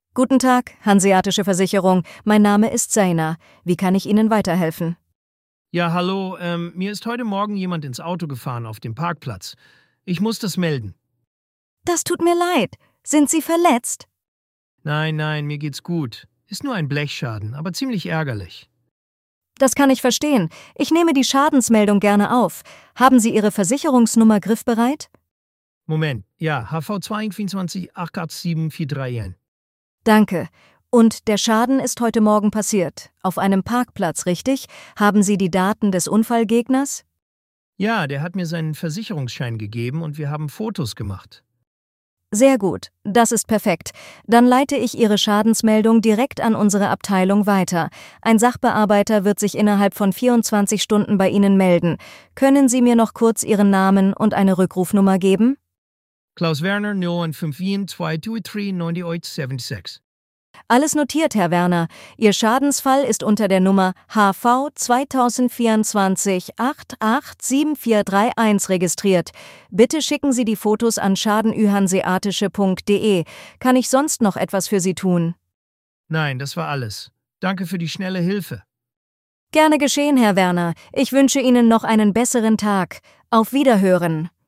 Wählen Sie Ihre Sprache und hören Sie echte KI-Gespräche.
voice-demo-insurance.mp3